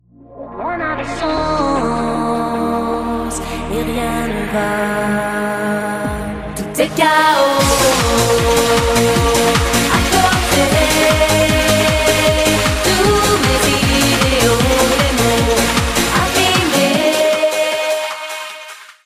extrait remixé
style musical électro-pop moderne